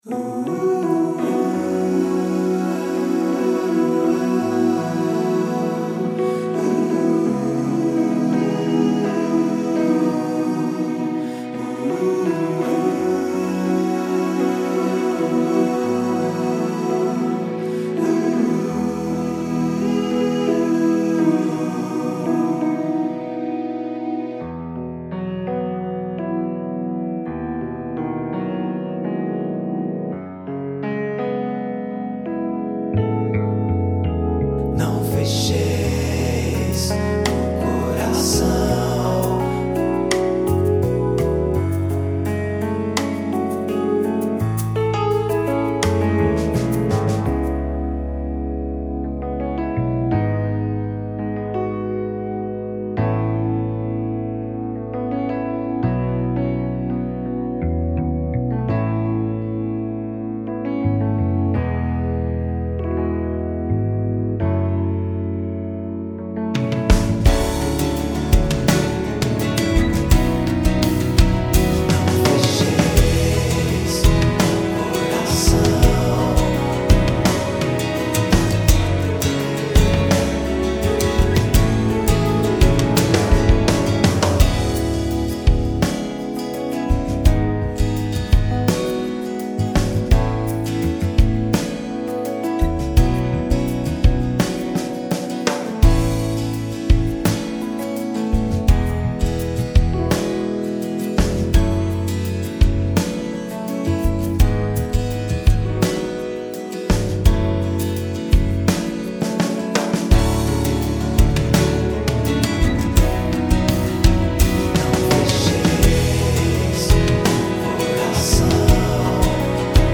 PlayBack (música)